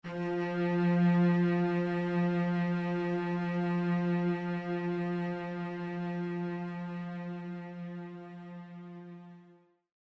Нота: Фа первой октавы (F4) – 349.23 Гц
Note5_F4.mp3